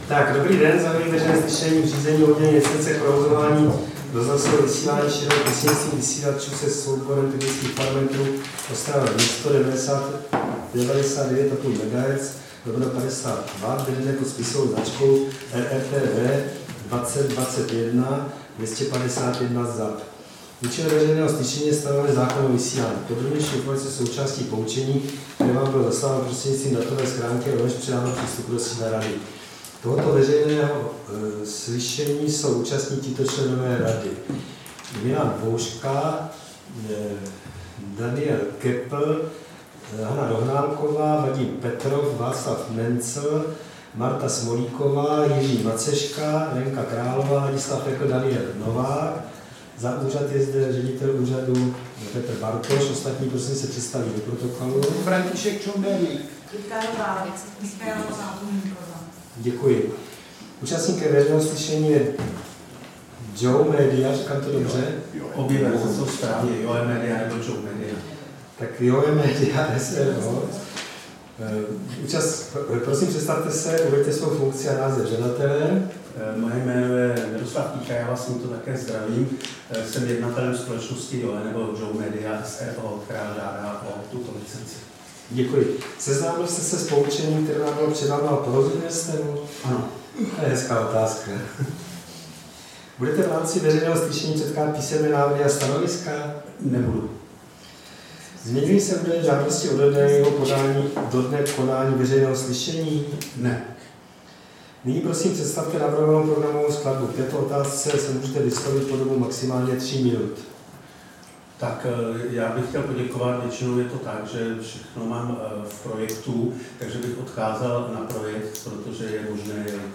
Veřejné slyšení v řízení o udělení licence k provozování rozhlasového vysílání šířeného prostřednictvím vysílačů se souborem technických parametrů Ostrava-město 99,5 MHz/50 W
Místem konání veřejného slyšení je sídlo Rady pro rozhlasové a televizní vysílání, Škrétova 44/6, PSČ 120 21, Praha 2 Vinohrady.